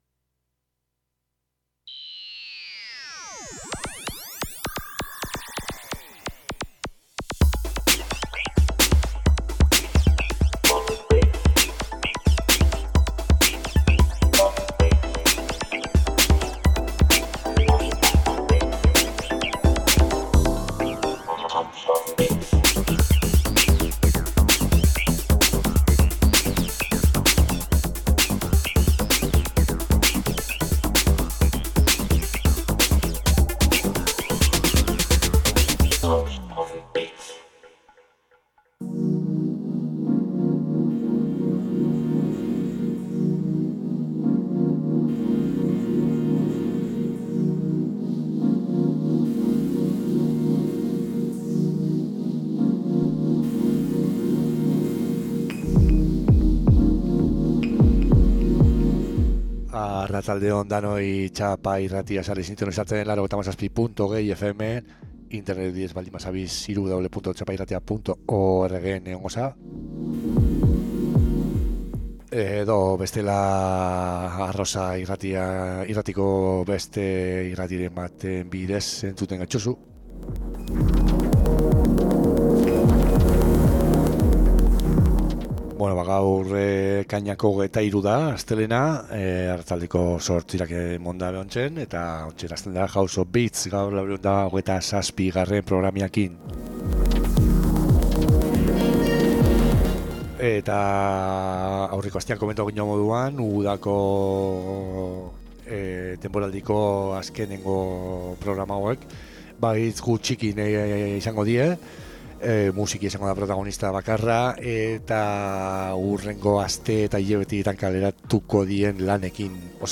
Txapa irratian elektronika kultura sustatzen duen irratsaioa. Elkarrizketak, sesioak, jai alternatiboen berriak eta musikaz gozatzeko asteroko saioa.